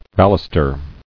[bal·us·ter]